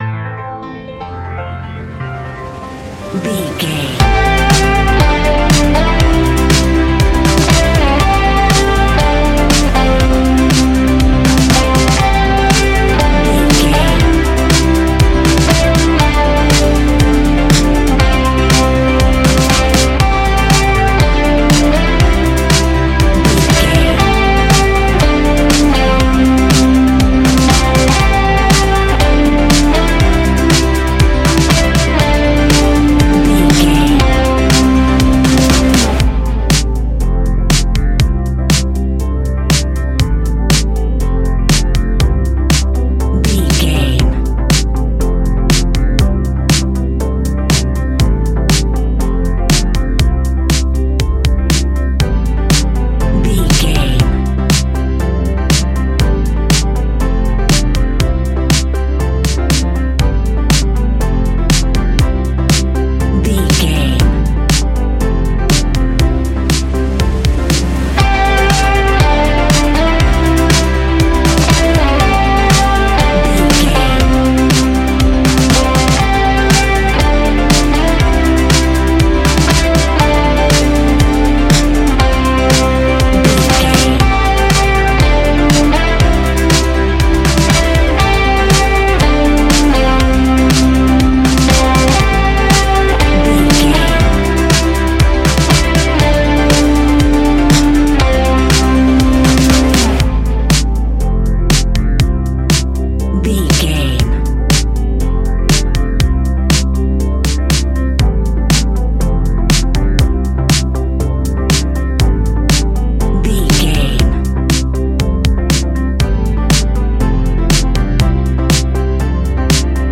Ionian/Major
ambient
electronic
new age
chill out
downtempo
synth
pads
drone